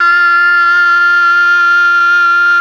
RED.OBOE  23.wav